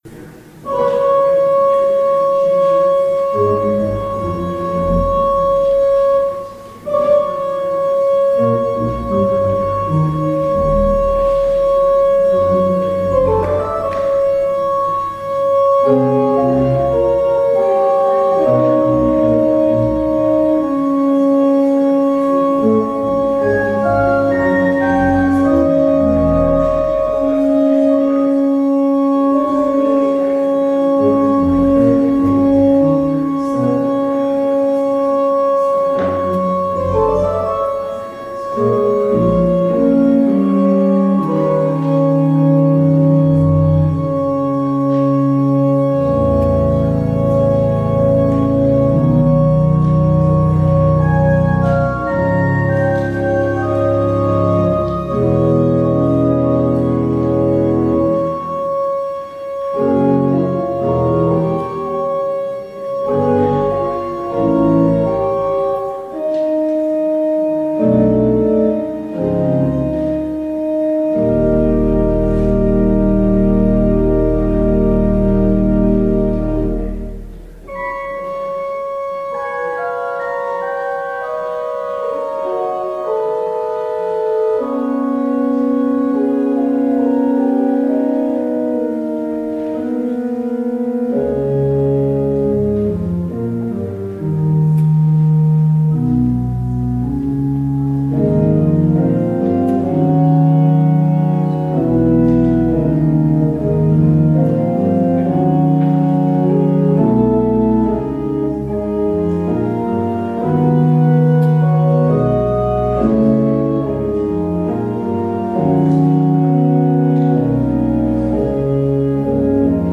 Audio recording of the 10am service